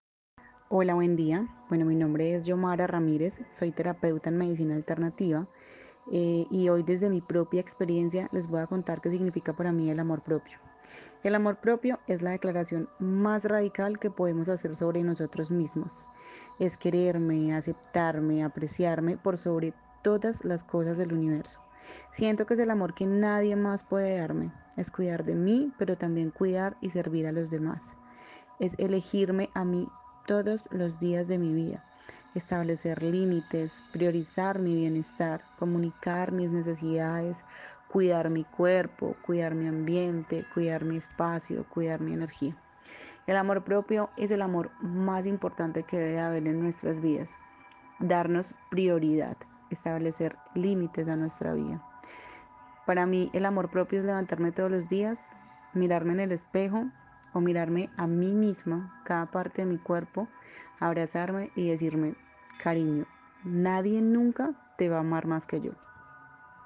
Narración oral de una mujer que vive en Bogotá y que desde su experiencia define el amor propio como el a aceptarse y quererse por encima de todas las cosas, así como poner límites al priorizar su bienestar y comunicar sus necesidades. Destaca que el amor propio también es cuidarse y a su vez, cuidar y servir a los demás.